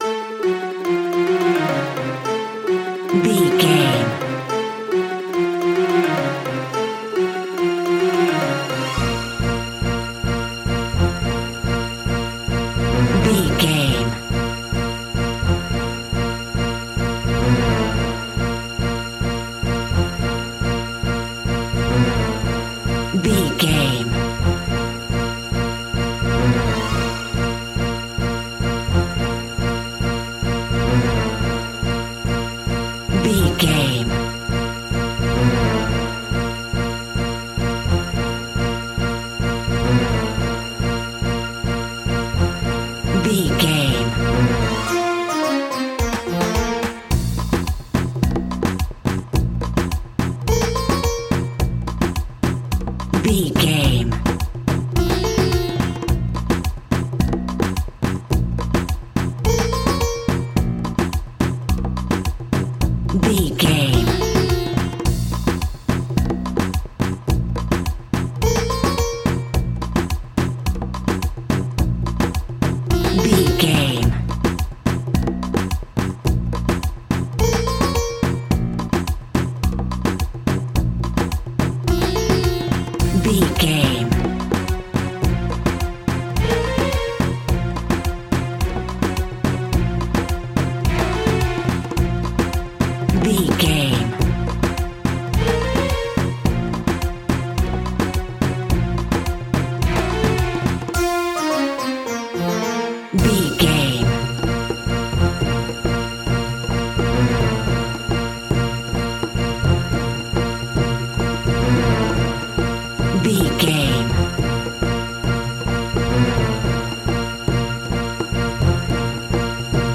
Aeolian/Minor
B♭
World Music
percussion
congas
bongos
kora
djembe
kalimba
talking drum
marimba